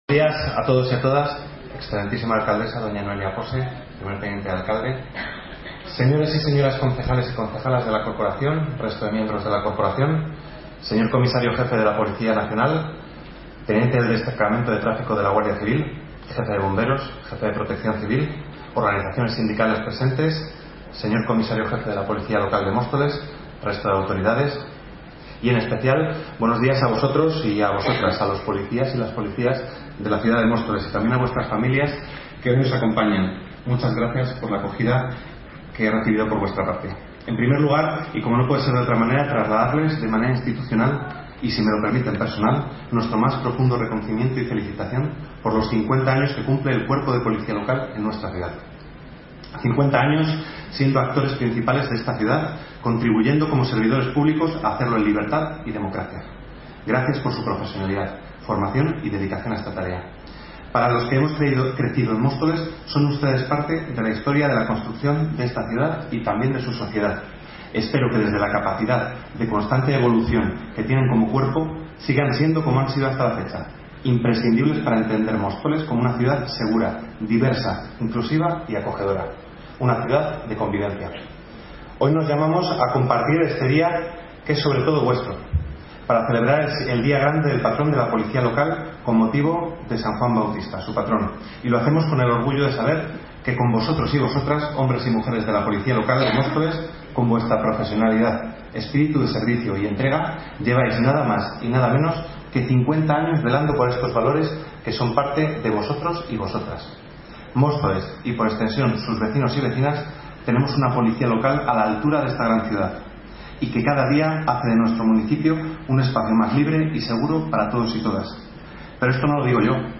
Audio - Alejandro Martín Jiménez (Concejal de Seguridad) Sobre Patrón Policía Municipal